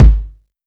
Tuned kick drum samples Free sound effects and audio clips
• Small Reverb Bass Drum Single Hit G# Key 658.wav
Royality free bass drum sample tuned to the G# note. Loudest frequency: 146Hz
small-reverb-bass-drum-single-hit-g-sharp-key-658-uQK.wav